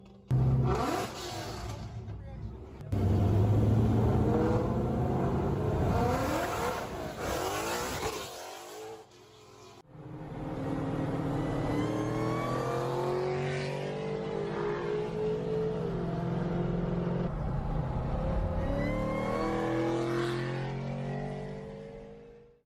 supercharger-Mixdown-1.mp3